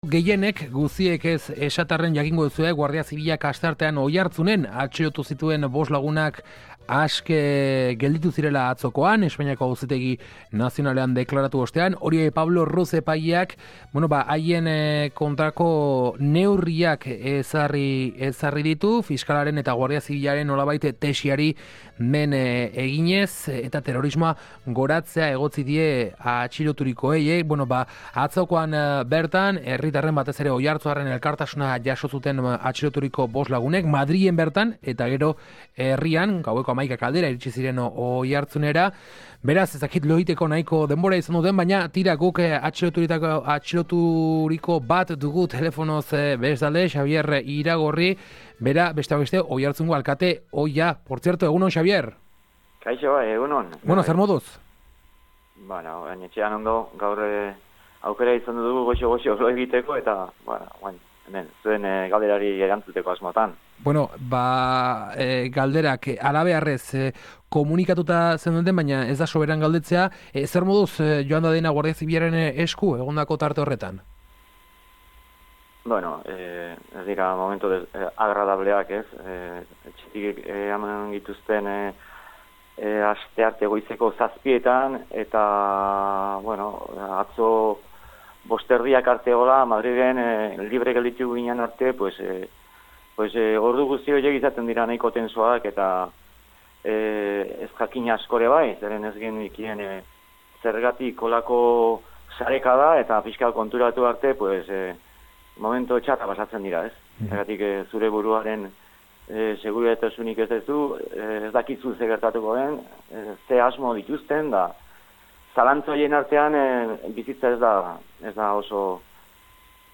Guardia Zibilak asteartean Oiartzunen atxilotu zituen bost lagunak askatu egin zituzten atzo, Espainiako Auzitegi Nazionalean deklaratu ondoren. Guk, haietako bat elkarrizketatu dugu gaurkoan, Oiartzungo alkate ohi Xabier Iragorri, hain zuzen.